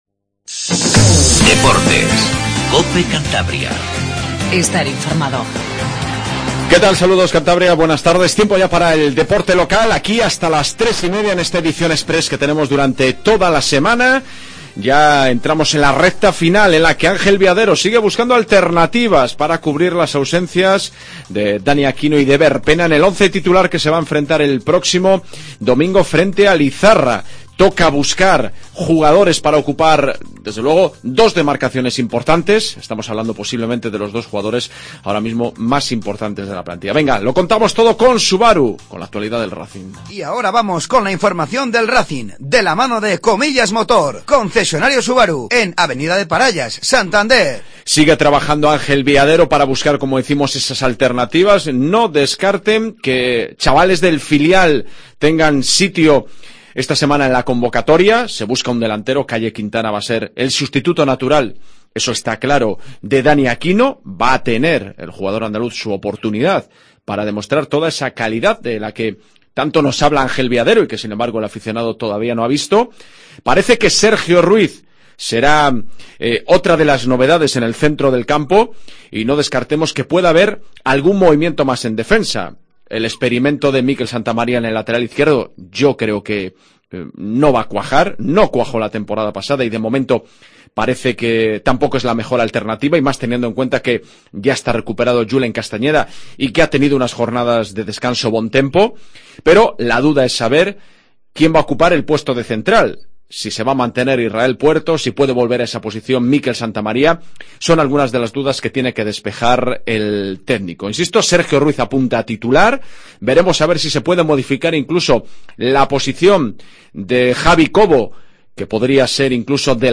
Noticias deportivas